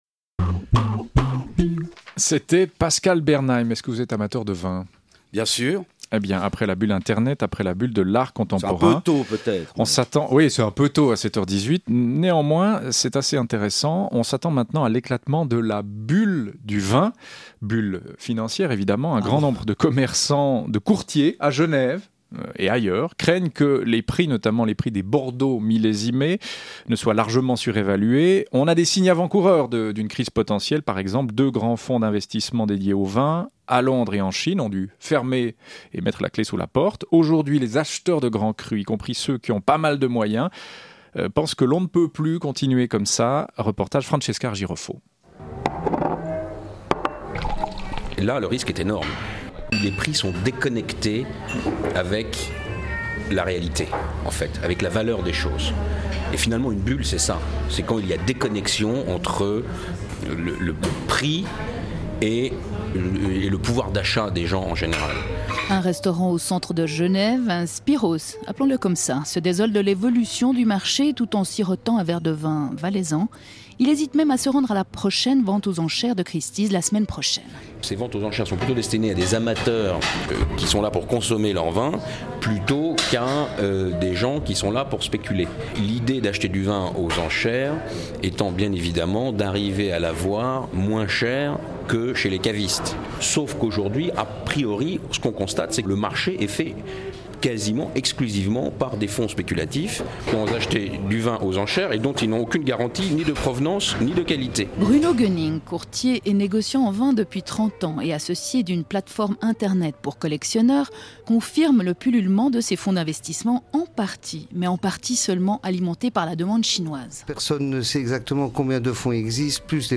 interview-winexpert.mp3